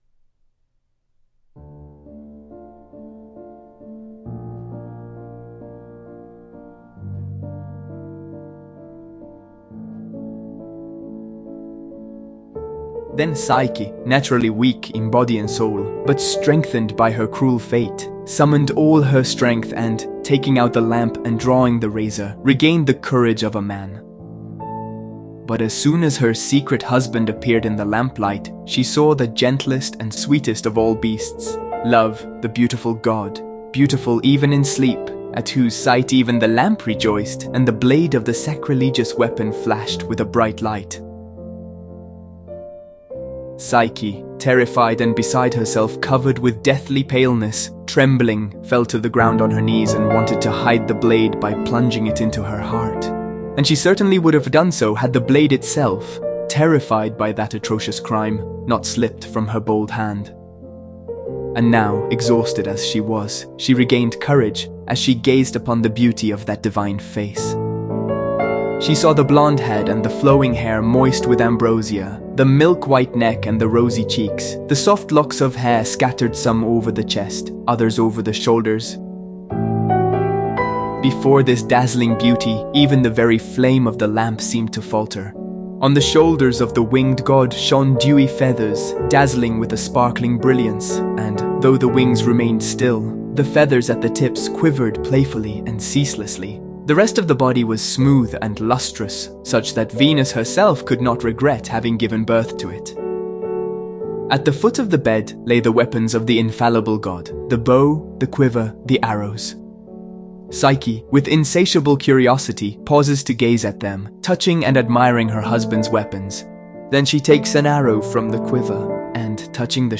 The soundtrack includes Schubert’s “Serenade” and “Trio, Op. 100”.